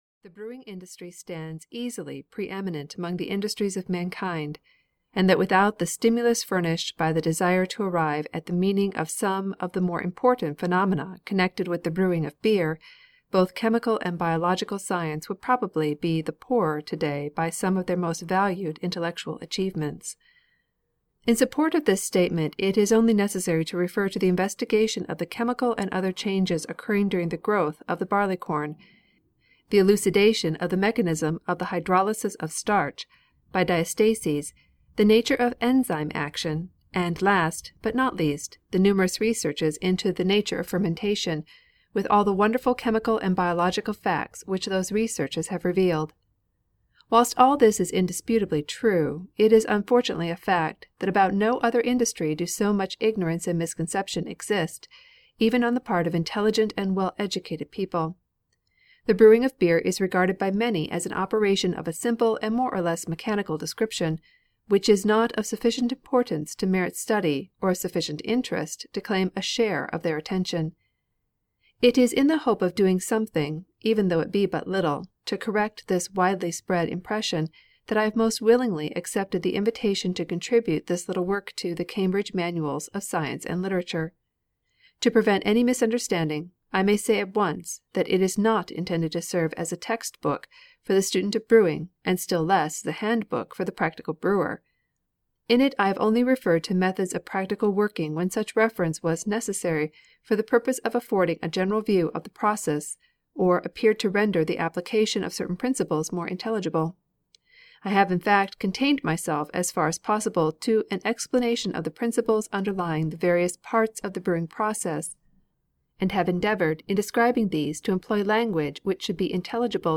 Brewing (EN) audiokniha
Ukázka z knihy